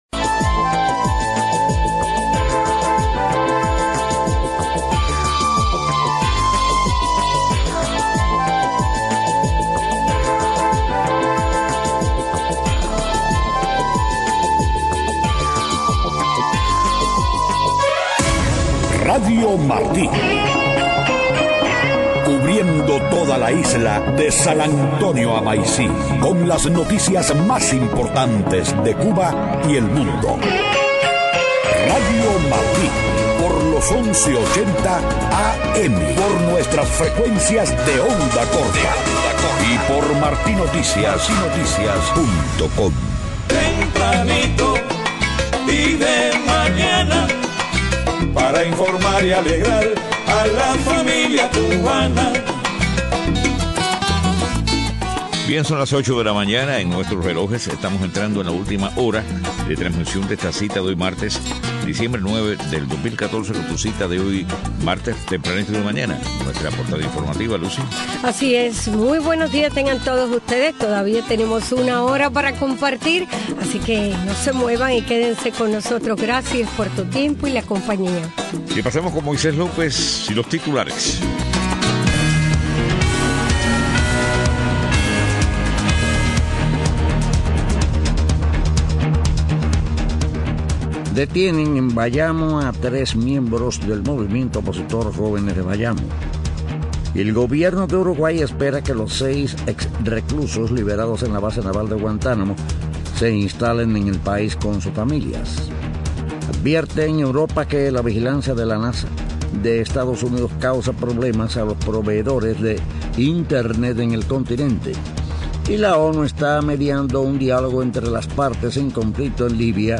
8:00 a.m. Noticias: Detienen en Bayamo a 3 miembros del movimiento opositor “Jóvenes de Bayamo”. Gobierno uruguayo espera que los 6 presos detenidos por 12 años en la base de Guantánamo se instalen allí con sus familias.